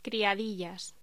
Locución: Criadillas
voz